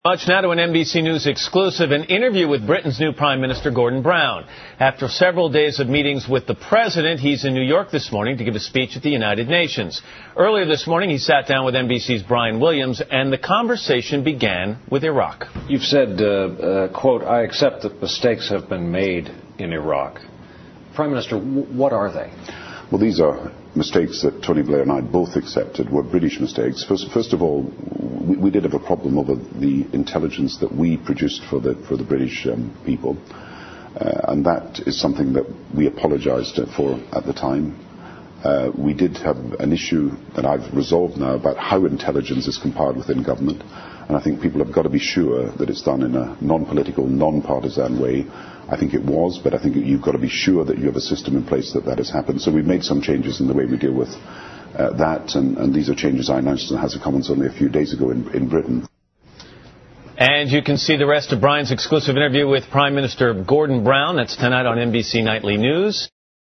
访谈录 Interview 2007-08-08&08-09, 专访英首相布朗 听力文件下载—在线英语听力室